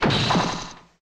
ANIME - WHACK 02
Category: Sound FX   Right: Commercial